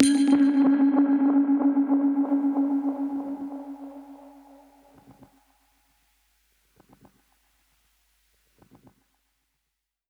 Index of /musicradar/dub-percussion-samples/95bpm
DPFX_PercHit_A_95-04.wav